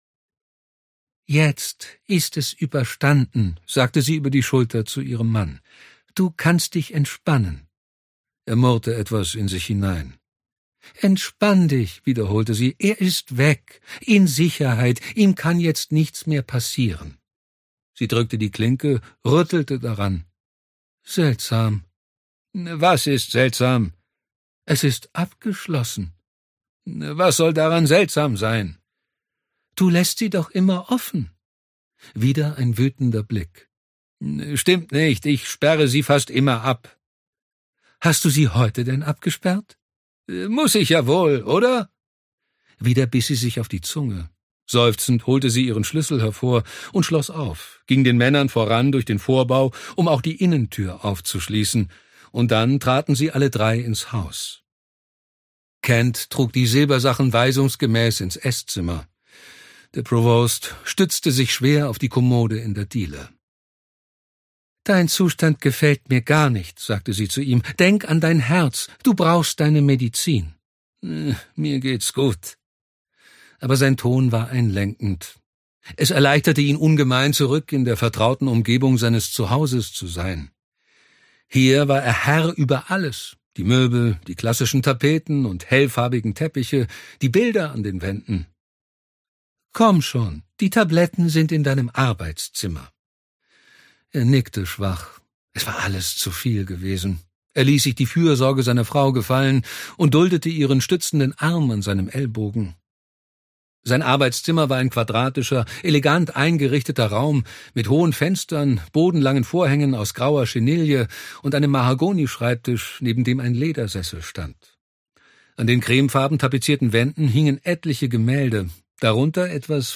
Ausgabe: Ungekürzte Lesung